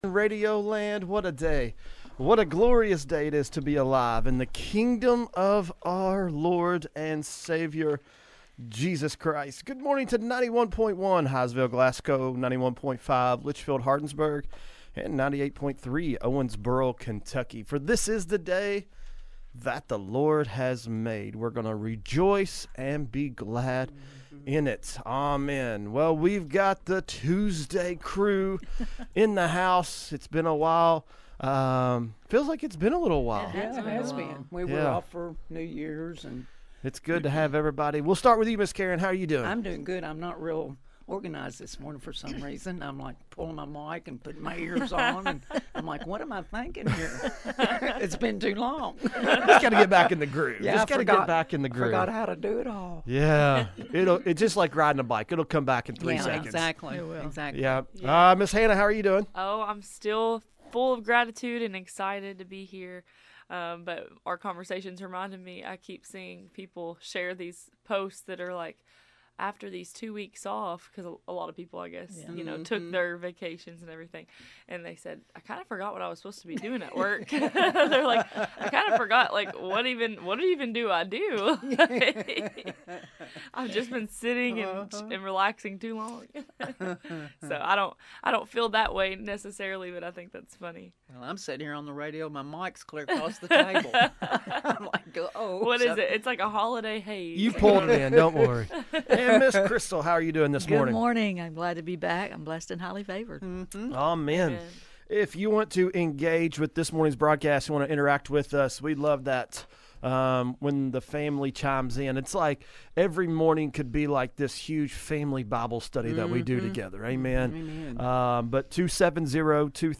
MBR: A Conversation on Angels and the Power of Praise – Box 2 Radio Network